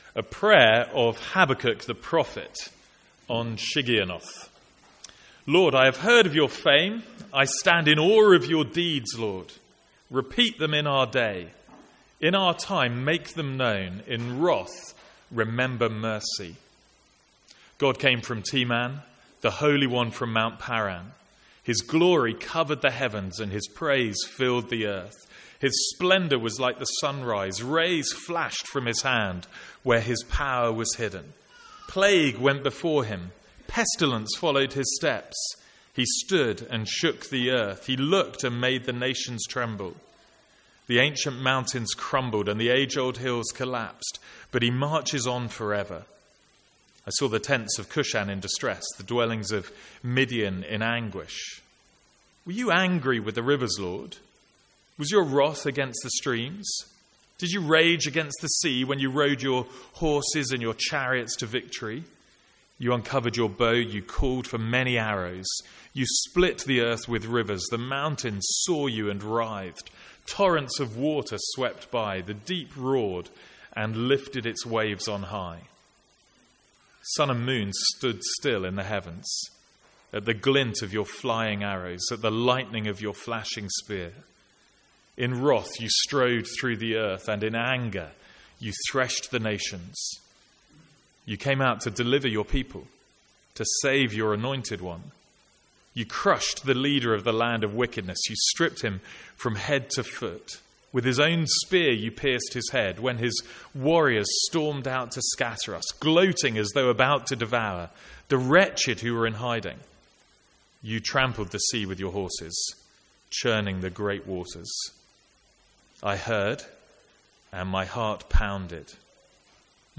Sermons | St Andrews Free Church
From the Sunday morning series in Habakkuk.